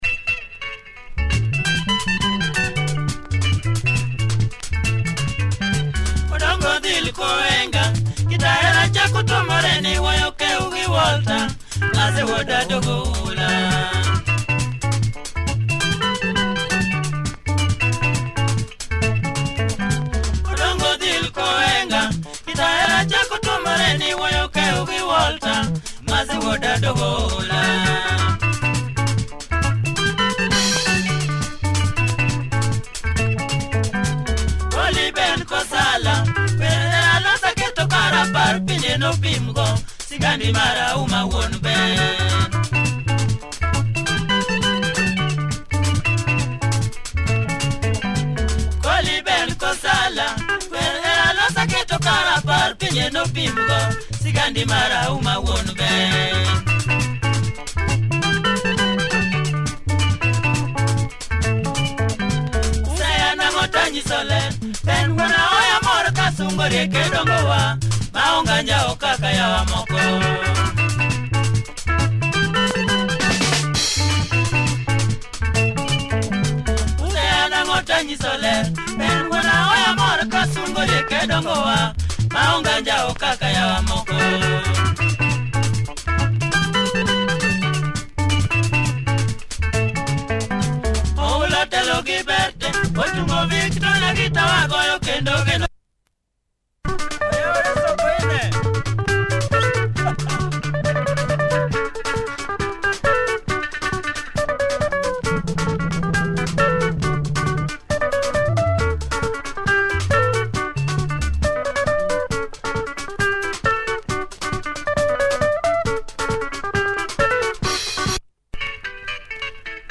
Great Luo benga